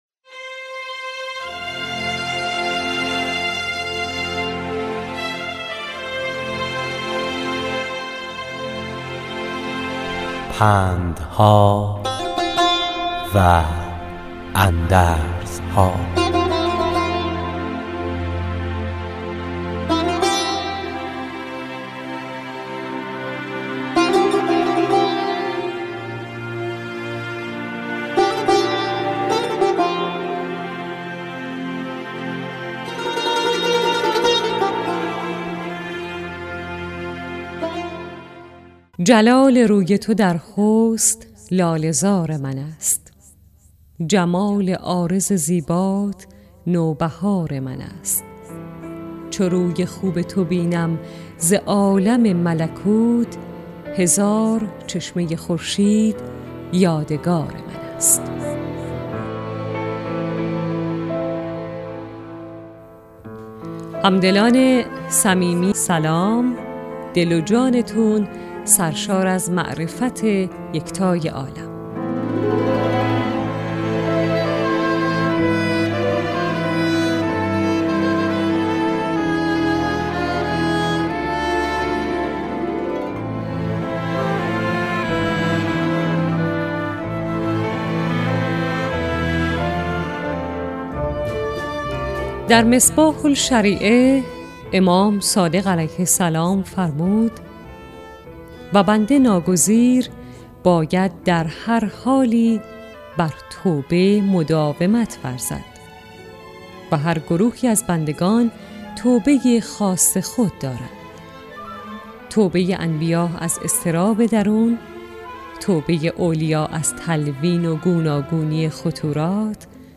در برنامه " پندها و اندرزها"، راوی برای شنوندگان عزیز صدای خراسان، حکایت های پندآموزی را روایت می کند .